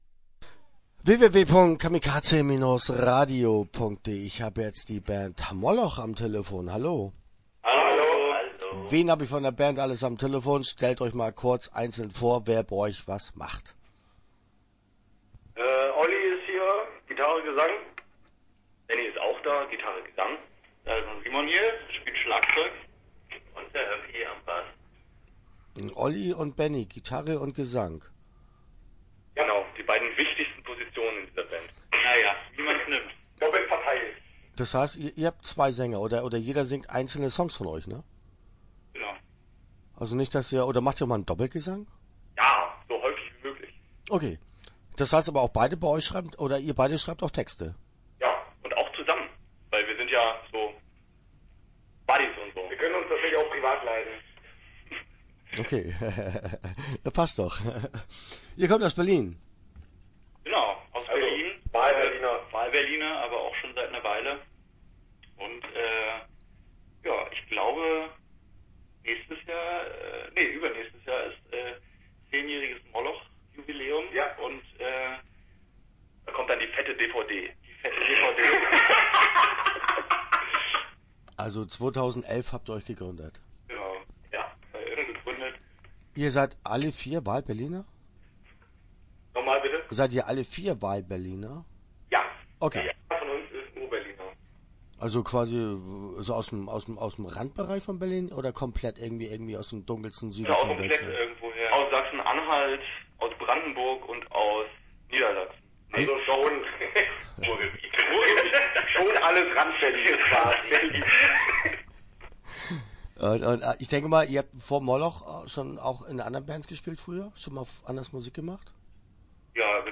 Moloch - Interview Teil 1 (11:52)